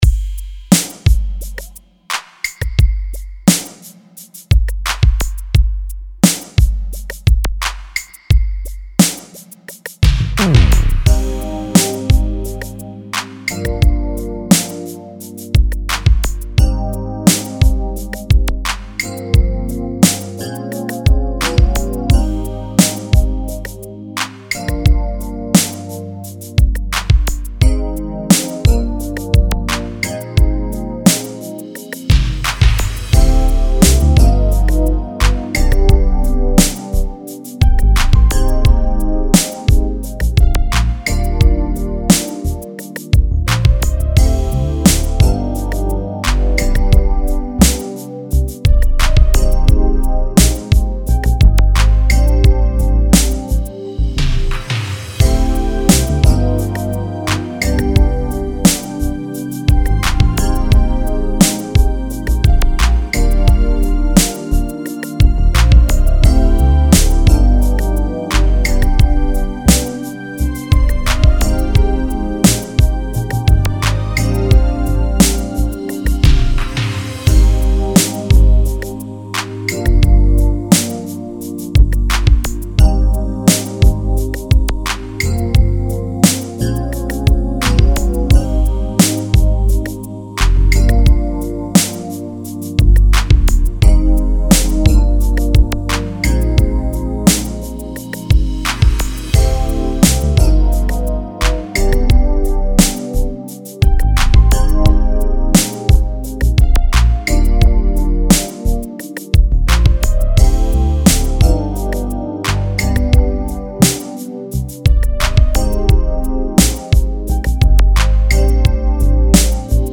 80s, 90s, Hip Hop
Eb